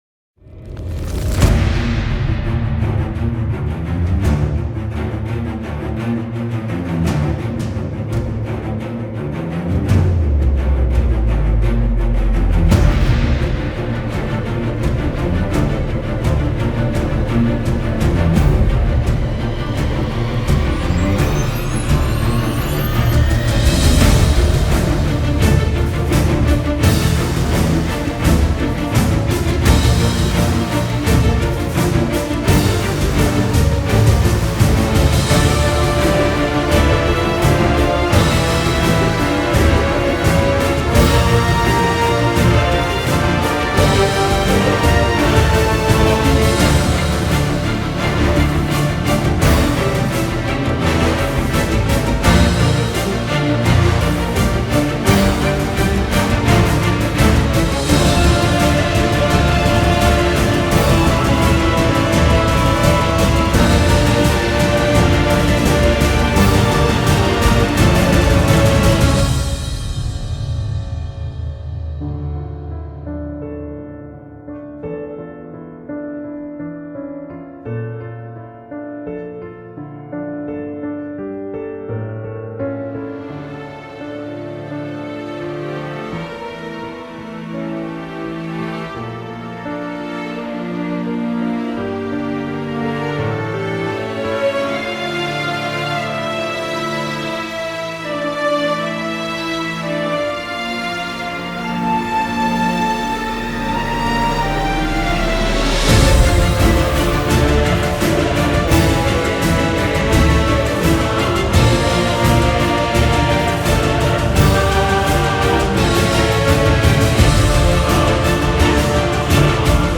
KFfq5wWICqU_most-epic-battle-music-ever-into-a-dark-dawn.mp3